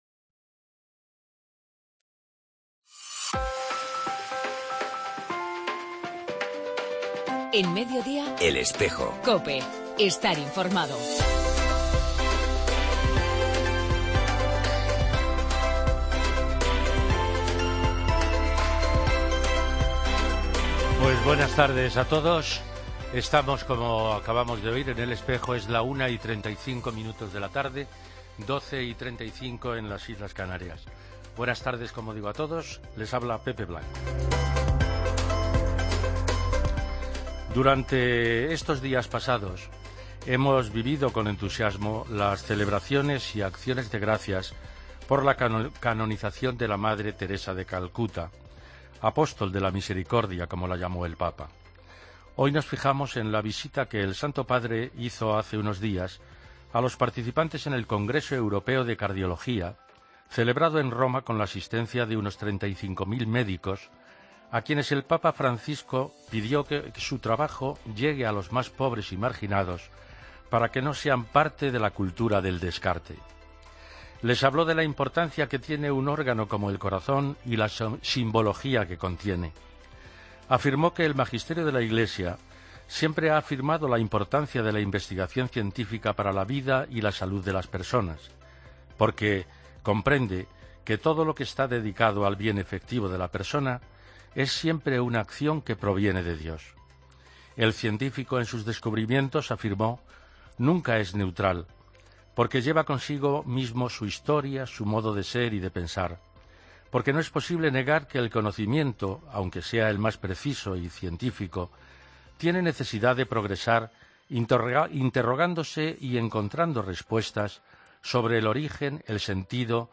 En El Espejo del 9 de septiempre entrevistamos a tres misioneras franciscanas